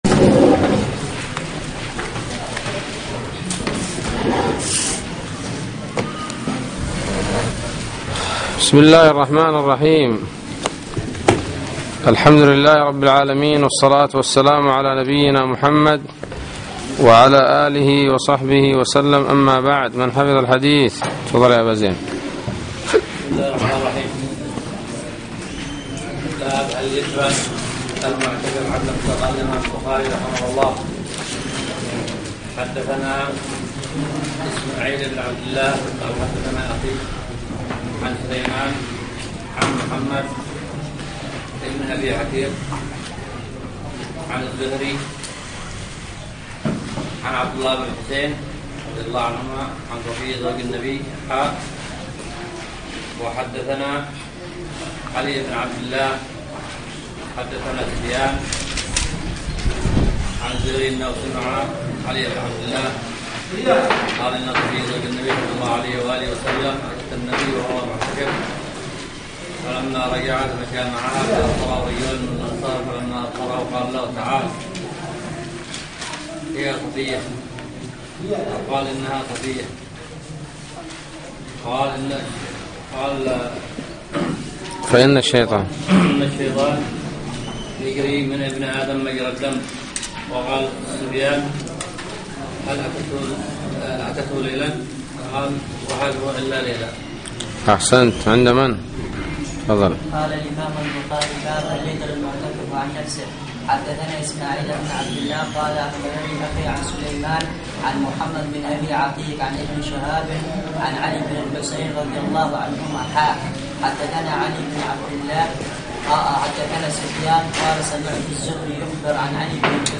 الدرس الثامن : باب الاعتكاف في شوال وباب من لم ير عليه صوما اذا اعتكف وباب اذا نذر في االجاهلية ان يعتكف ثم أسلم